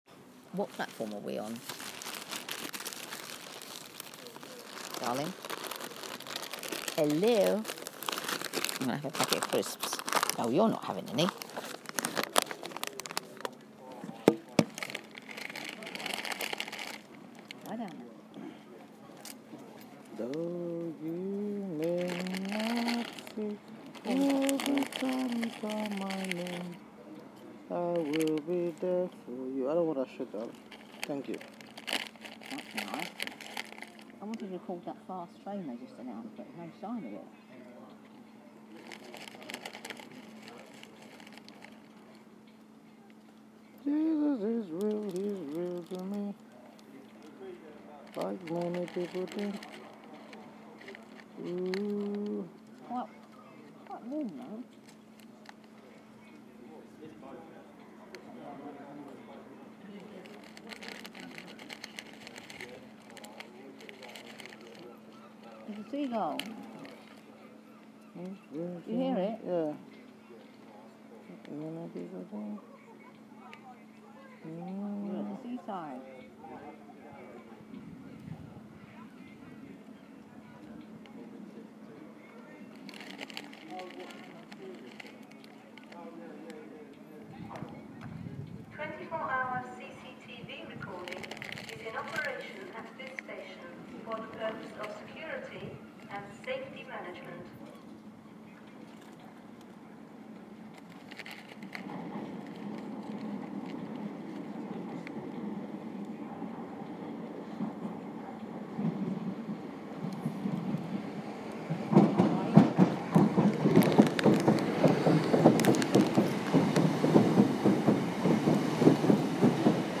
Waiting at St Leonards Warrior Square station